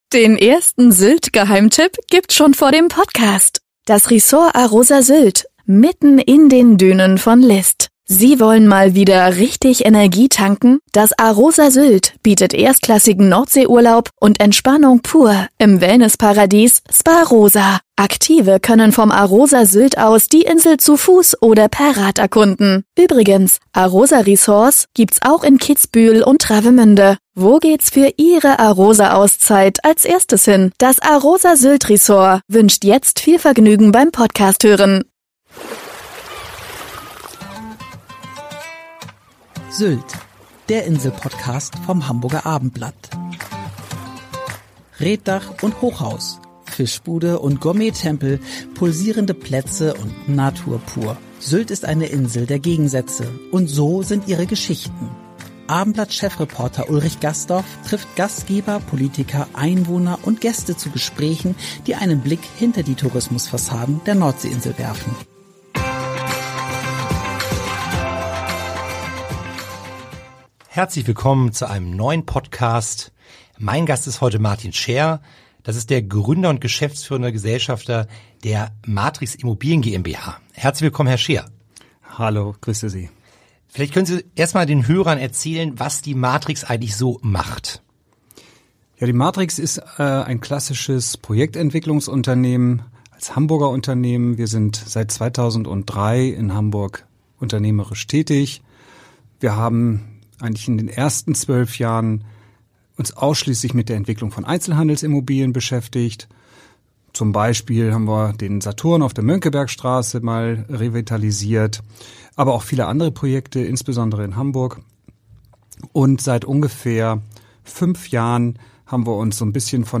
Sylt – der Insel-Talk